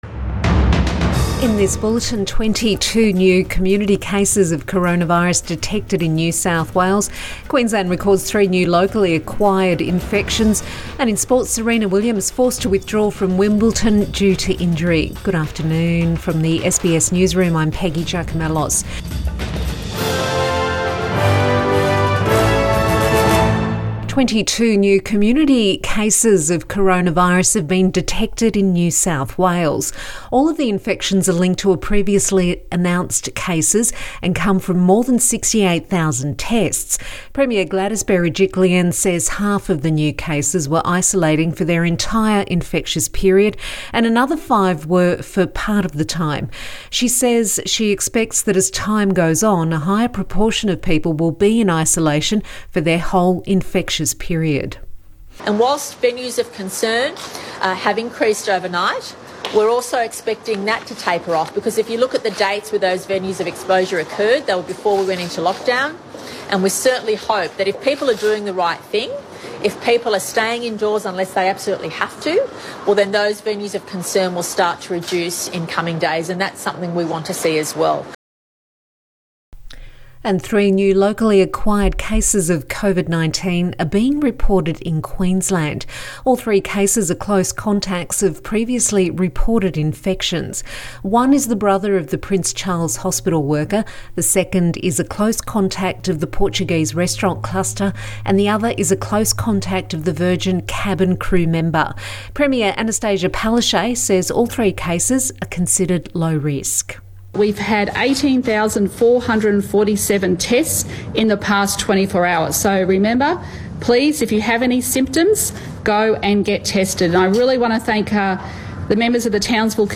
Midday bulletin 30 June 2021